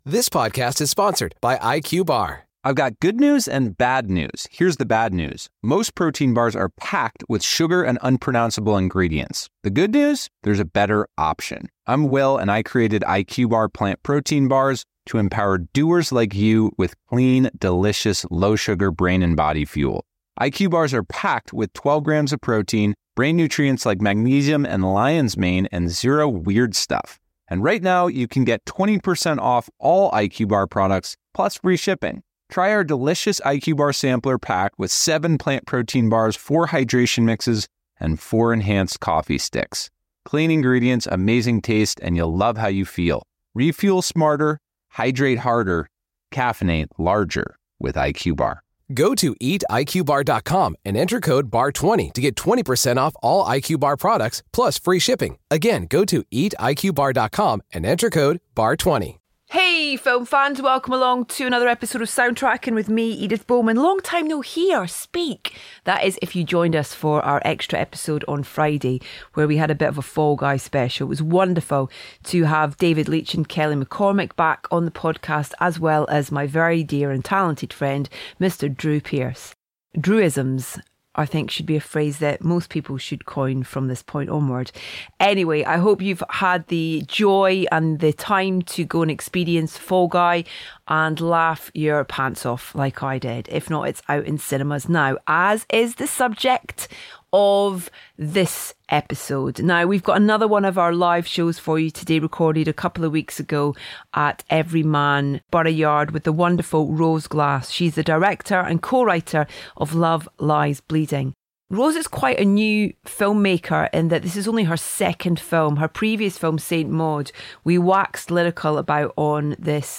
We have another one of our live Everyman Soundtracking shows for you today, recorded with Rose Glass, director and co-writer of Love Lies Bleeding.